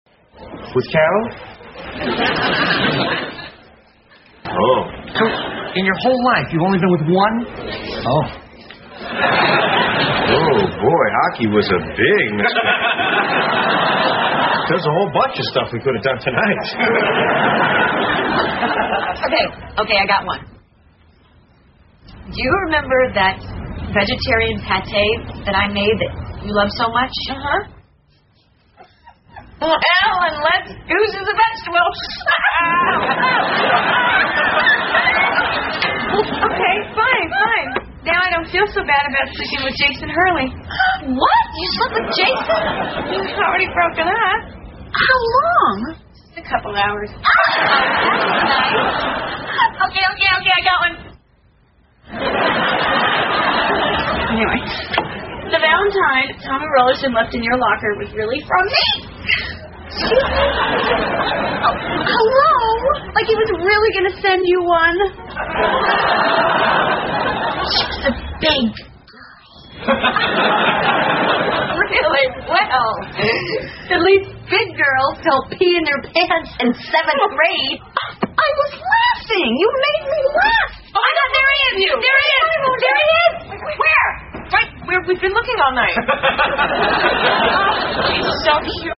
在线英语听力室老友记精校版第1季 第45期:克林顿亲信助手(13)的听力文件下载, 《老友记精校版》是美国乃至全世界最受欢迎的情景喜剧，一共拍摄了10季，以其幽默的对白和与现实生活的贴近吸引了无数的观众，精校版栏目搭配高音质音频与同步双语字幕，是练习提升英语听力水平，积累英语知识的好帮手。